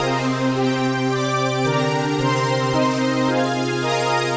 AM_VictorPad_110-C.wav